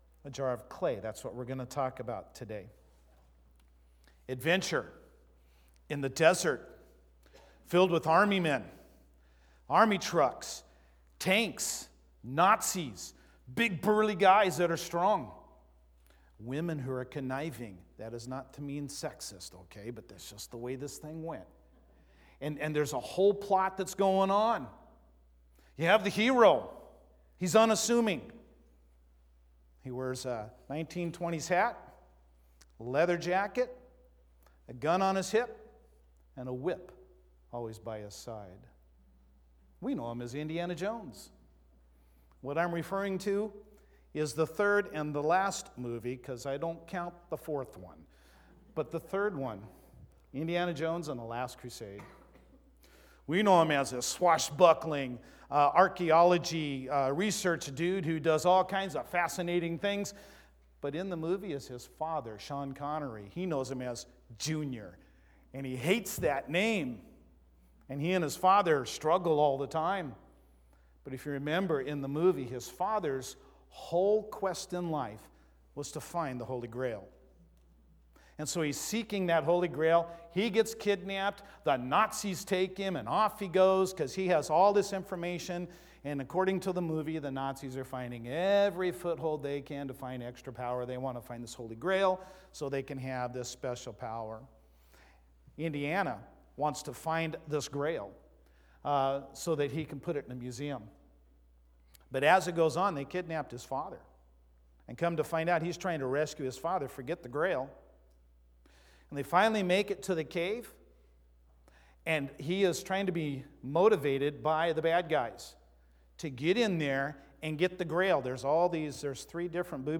1-26-20-Sermon.mp3